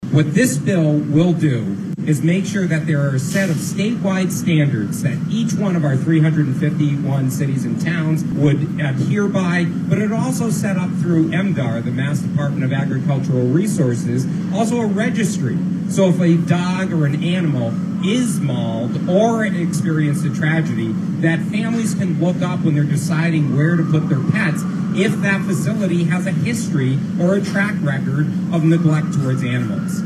A rally was held in front of the State House on Wednesday in support of Ollie’s Law which calls for statewide standards on the so-called “doggie day care industry” in Massachusetts.